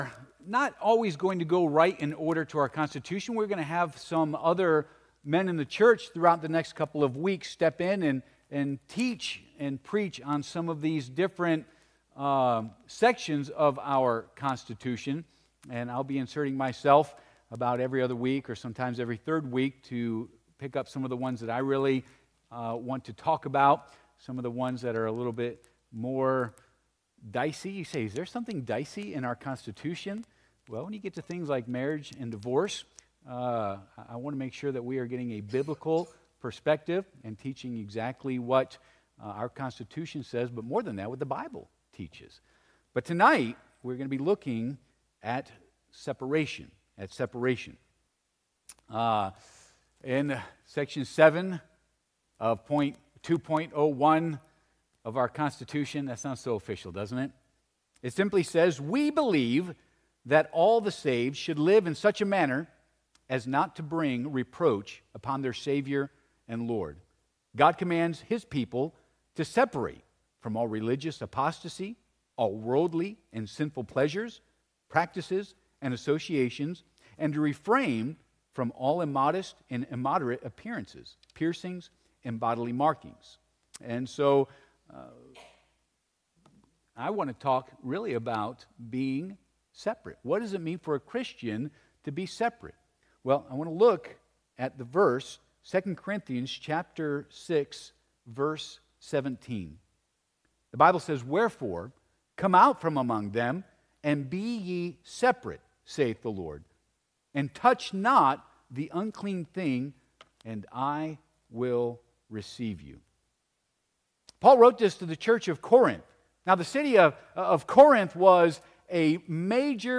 2 Cor. 6:17 Service Type: Sunday PM « The Gospel is for All!